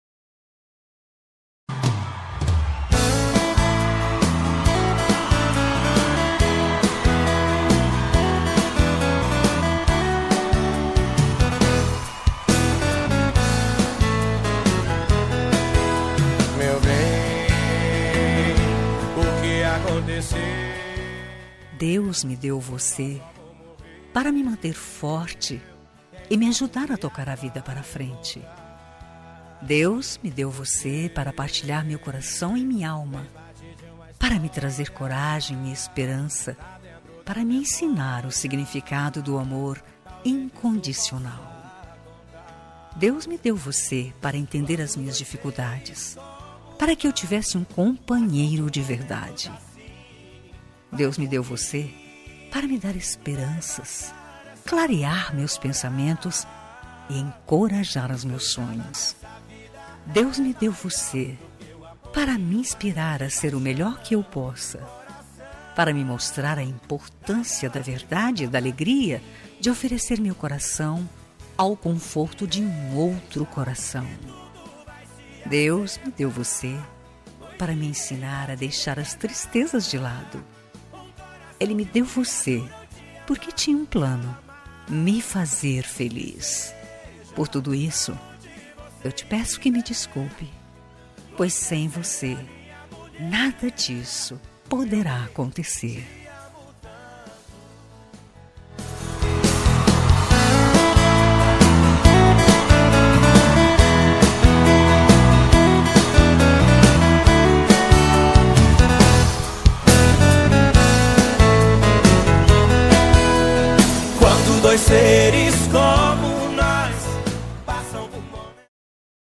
Telemensagem de Reconciliação – Voz Feminina – Cód: 8002 – Linda
8002-recon-fem-rom.m4a